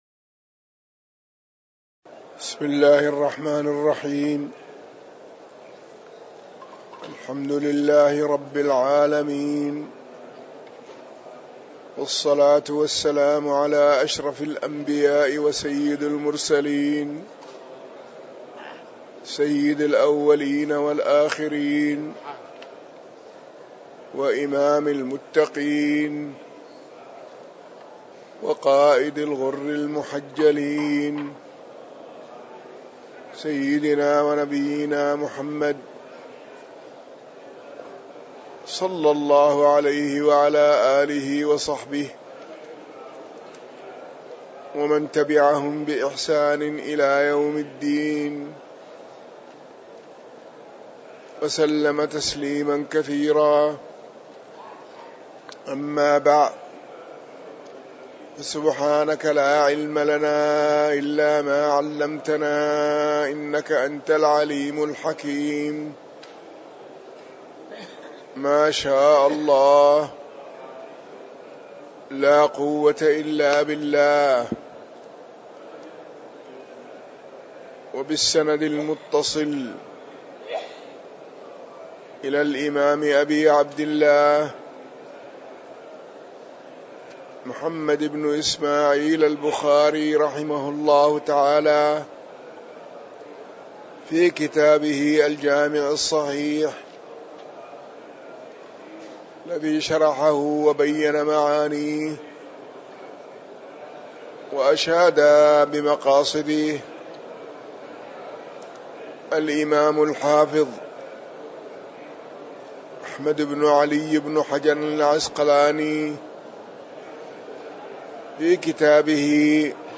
تاريخ النشر ١٢ ربيع الأول ١٤٤٠ هـ المكان: المسجد النبوي الشيخ